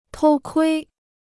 偷窥 (tōu kuī): to peep; to peek.